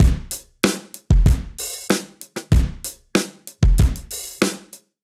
Index of /musicradar/sampled-funk-soul-samples/95bpm/Beats
SSF_DrumsProc1_95-03.wav